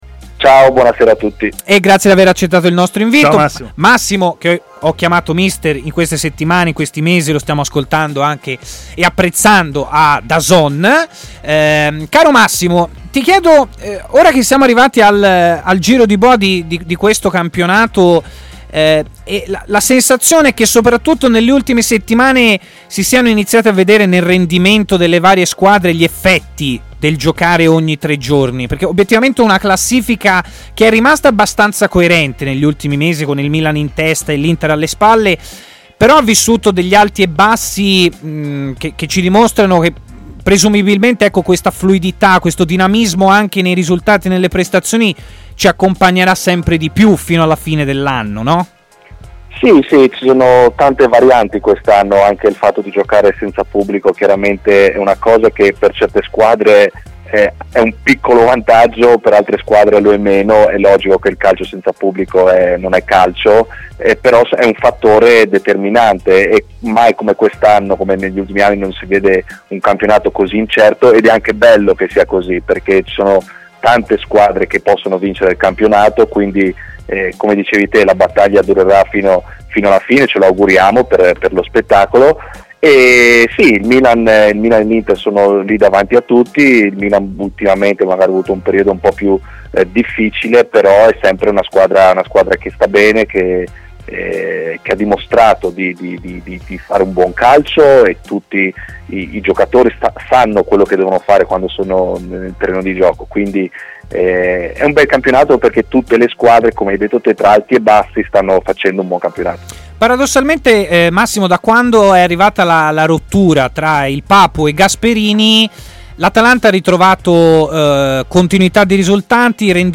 Stadio Aperto, trasmissione di TMW Radio